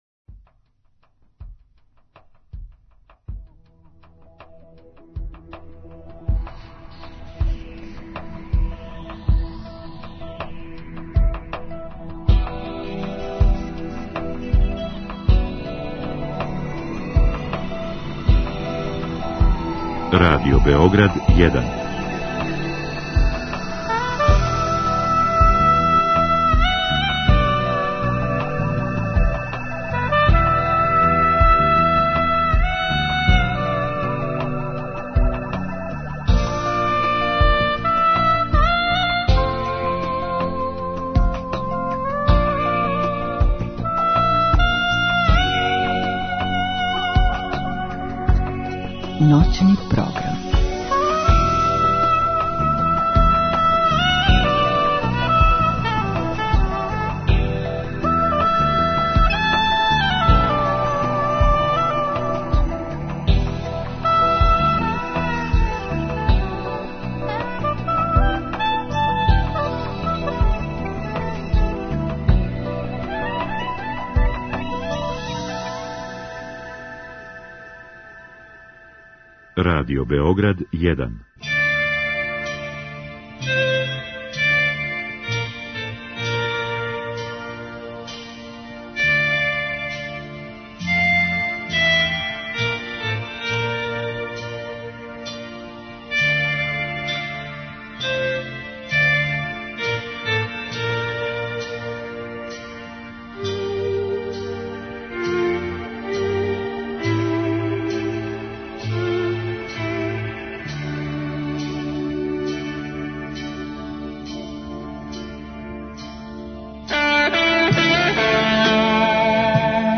Ексклузивно пренећемо делић атмосфере са овог значајног догађаја.